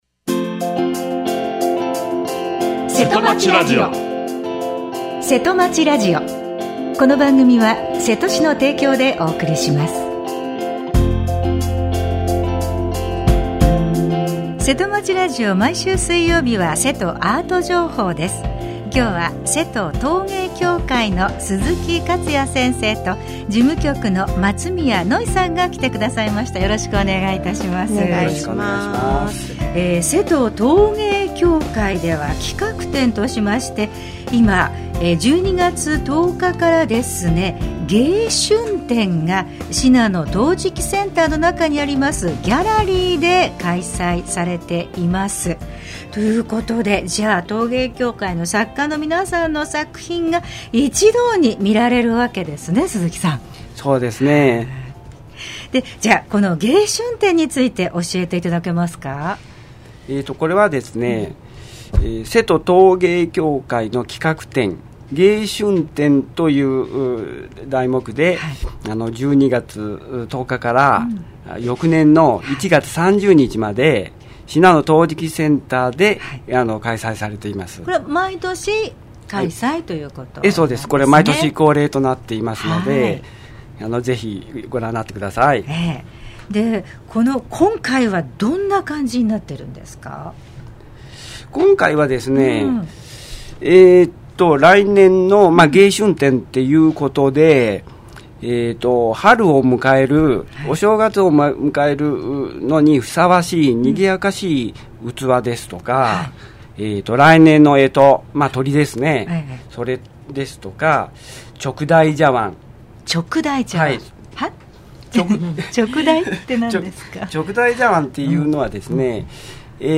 についてお話を伺いました。